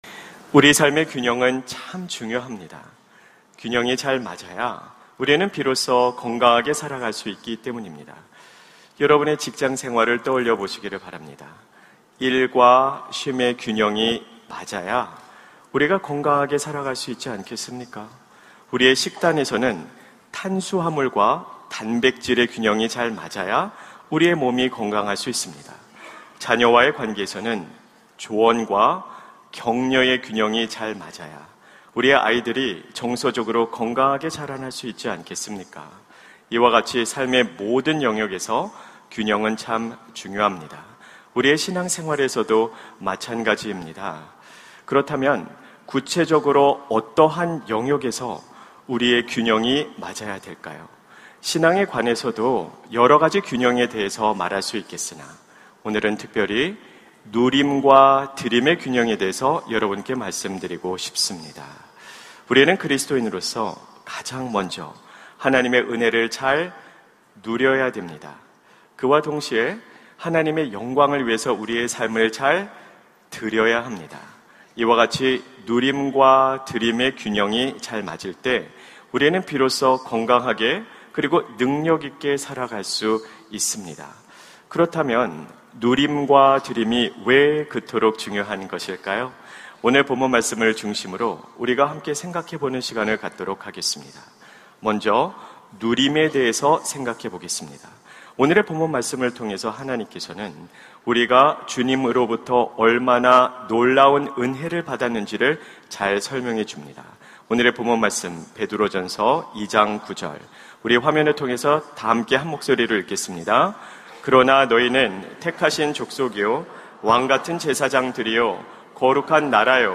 설교 : 주일예배 균형 신앙 : 기울어져 있지 않나요?